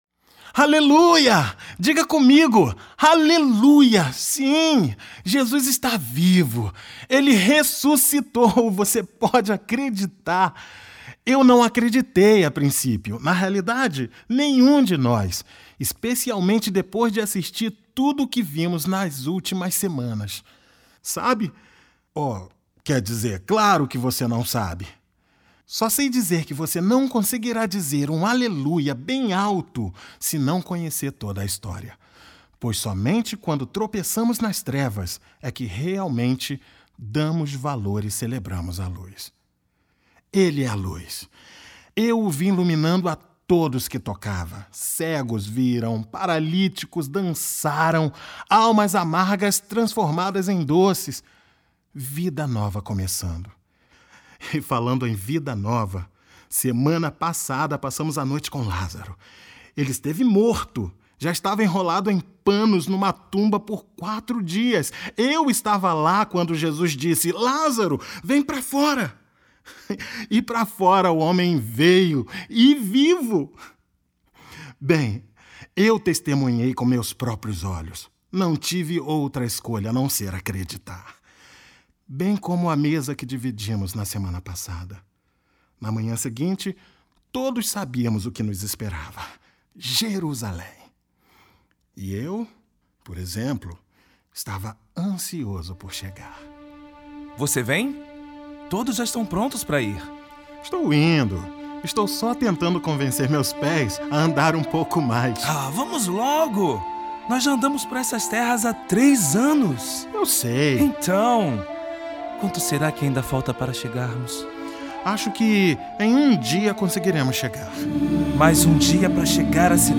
cantata de Páscoa